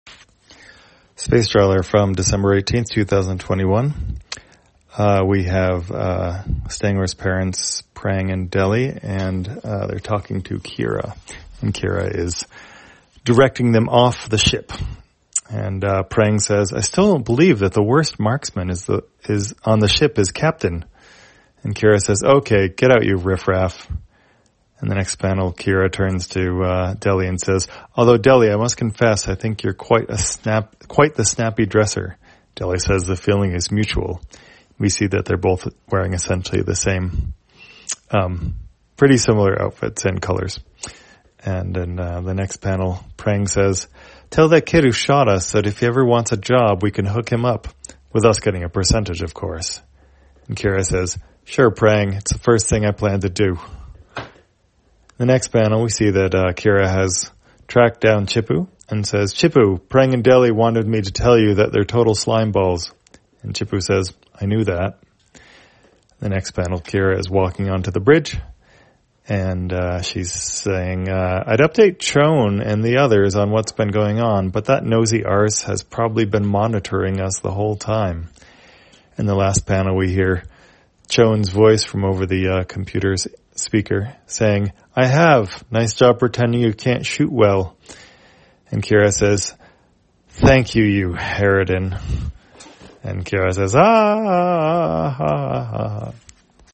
Spacetrawler, audio version For the blind or visually impaired, October 18, 2021.